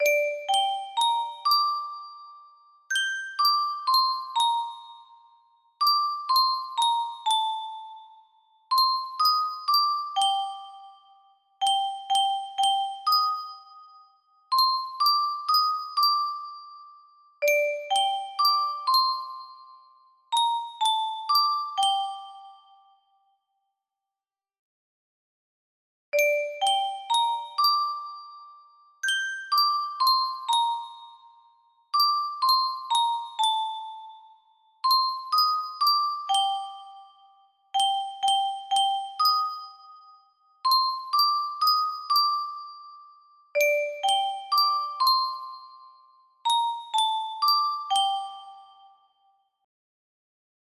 Clone of ILI ILI music box melody
an ilonggo lullaby